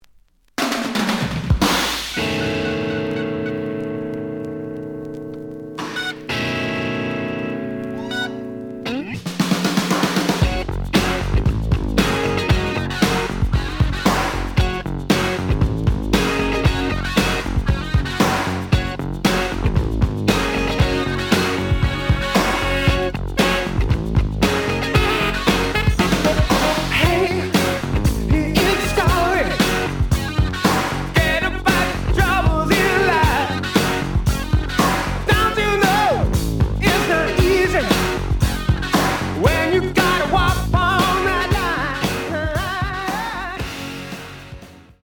試聴は実際のレコードから録音しています。
The audio sample is recorded from the actual item.
●Format: 7 inch
●Genre: Rock / Pop